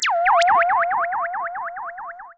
Theremin_FX_08.wav